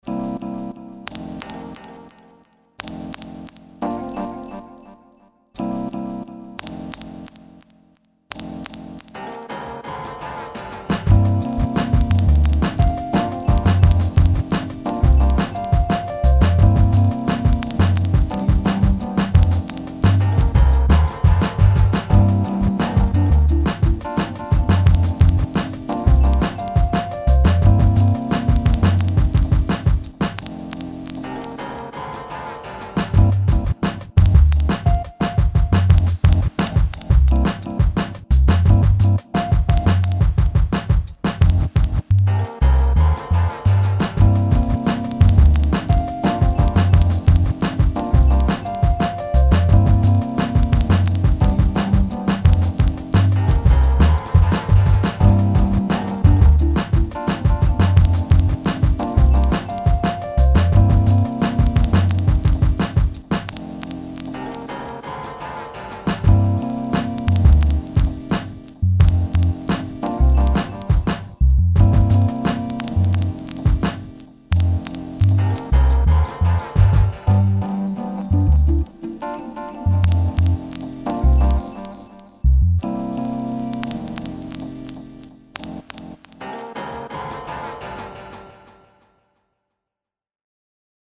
Vår Hiphop - Beats